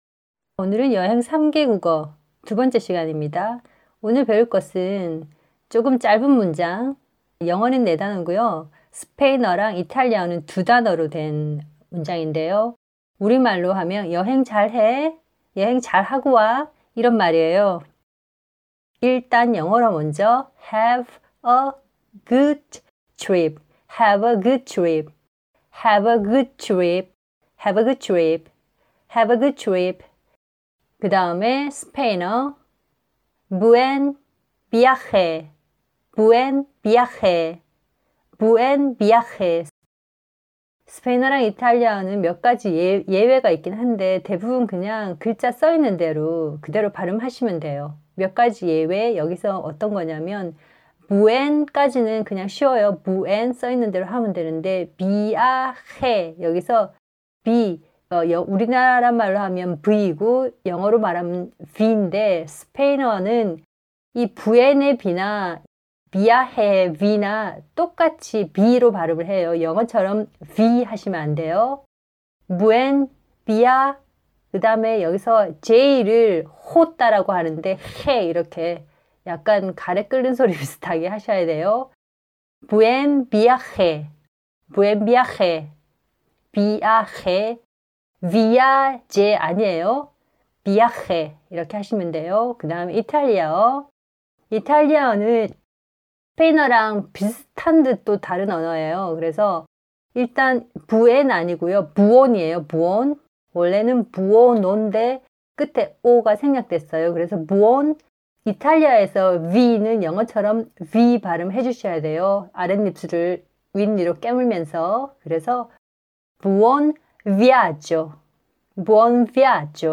/해버 구읃 츠륍/
▶스페인어 V는 B와 발음이 같아요.
윗니로 아랫입술 깨물고 발음해요.
목에서 "흐으"하고 소리내세요.